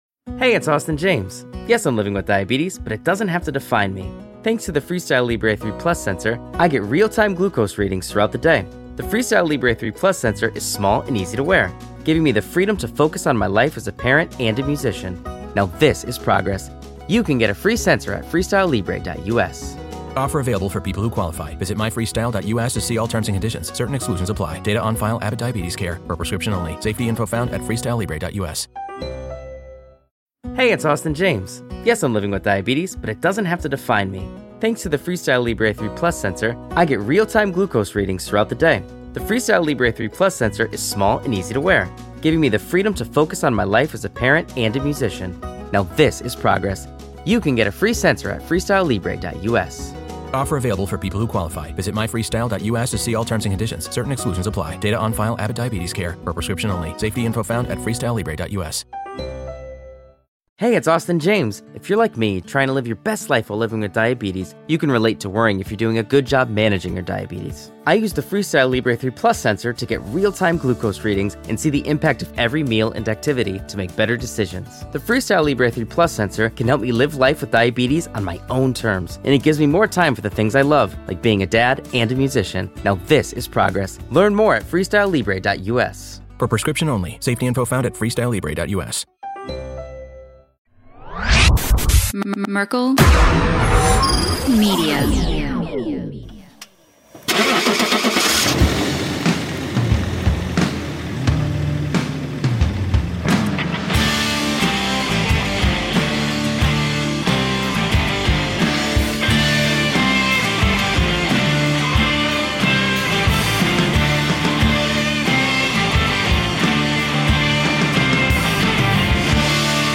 On Episode 22: Body On the Road, we have a two-part interview!